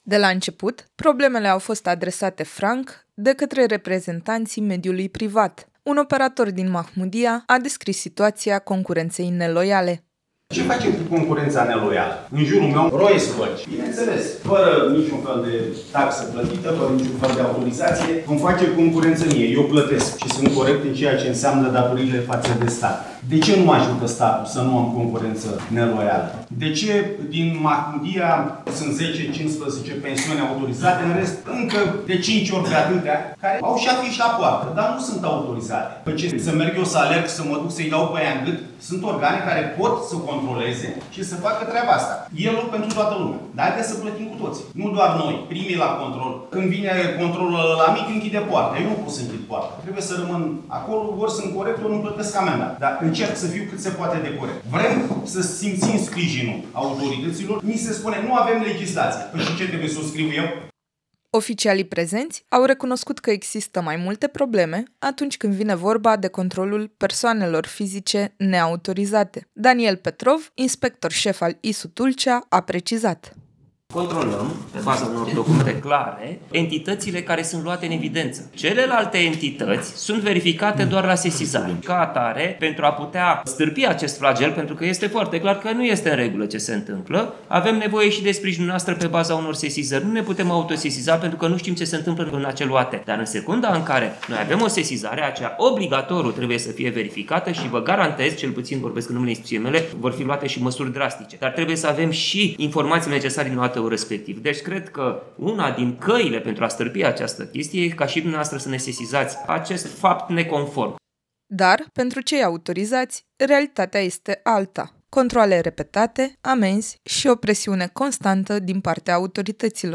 CONFERINTA-TURISM_LUNG.mp3